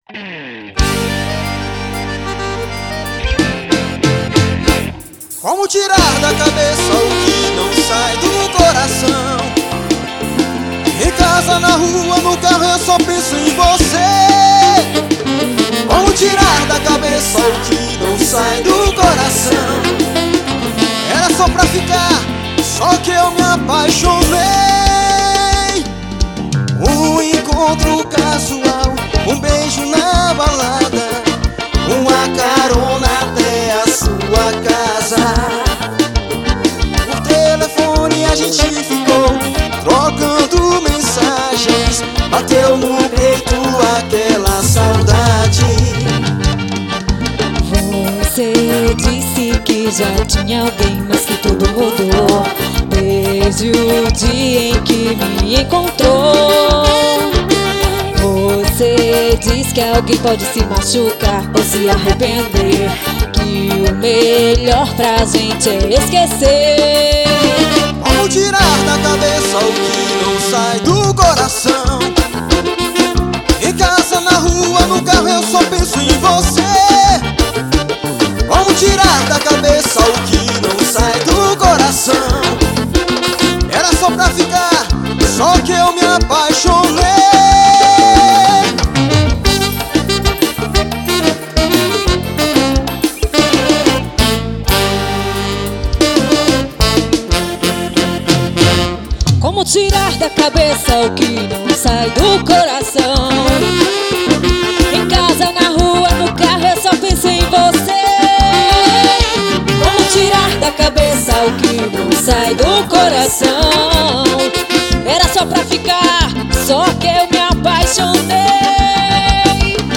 Ao vivo em Fortaleza.